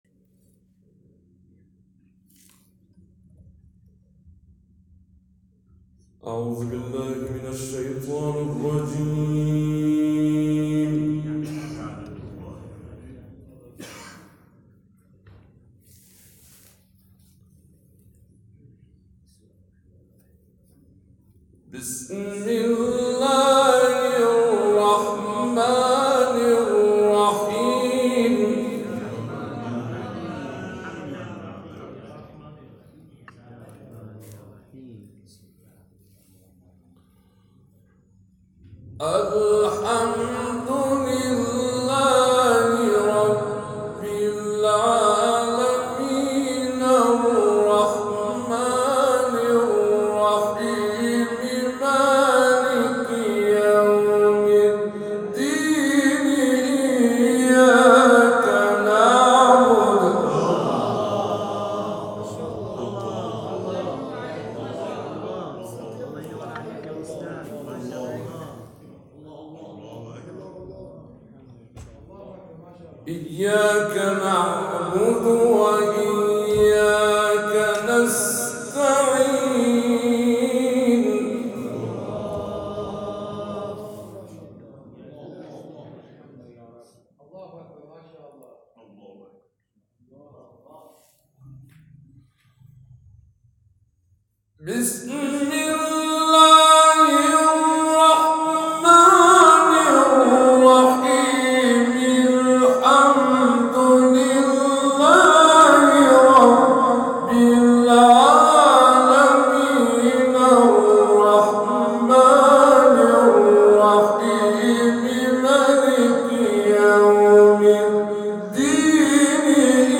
رمضان ۱۴۰۴ با قاریان افغانستانی + صوت